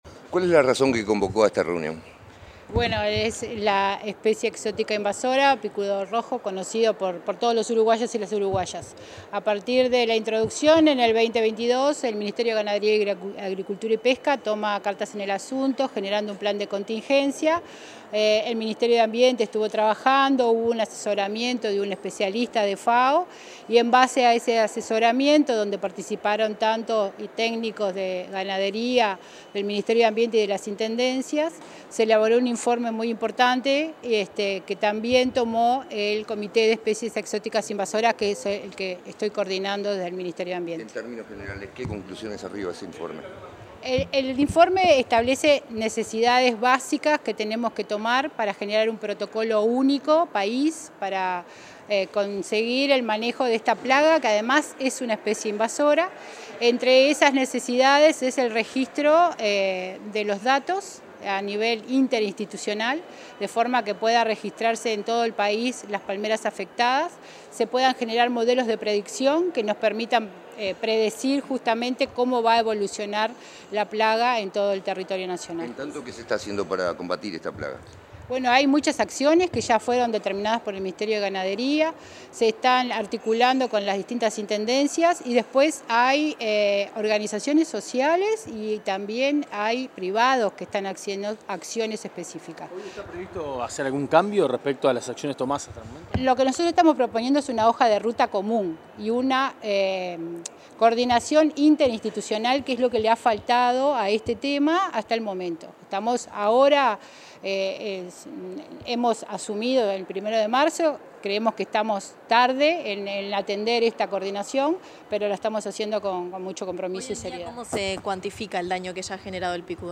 Declaraciones de la directora de Biodiversidad y Servicios Ecosistémicos, Estela Delgado
Declaraciones de la directora de Biodiversidad y Servicios Ecosistémicos, Estela Delgado 27/05/2025 Compartir Facebook X Copiar enlace WhatsApp LinkedIn La directora nacional de Biodiversidad y Servicios Ecosistémicos del Ministerio de Ambiente, Estela Delgado, realizó declaraciones a la prensa luego de una reunión del Comité de Especies Exóticas Invasoras, en la Torre Ejecutiva.